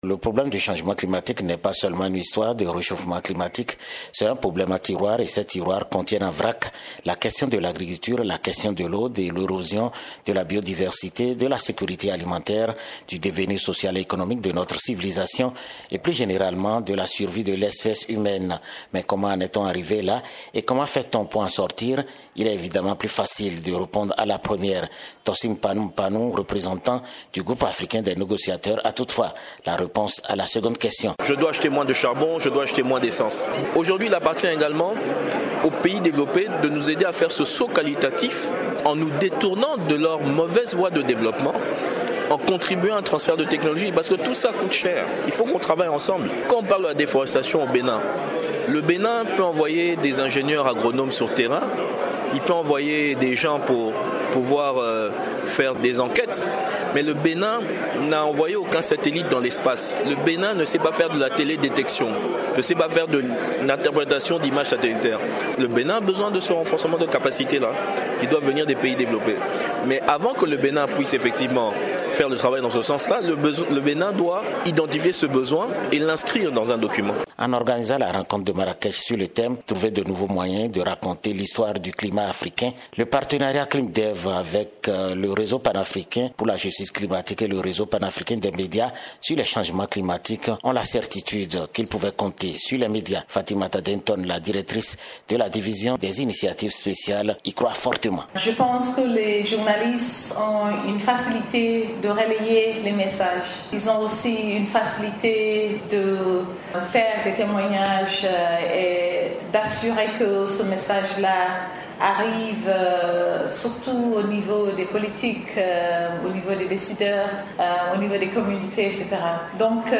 En ligne de Marrakech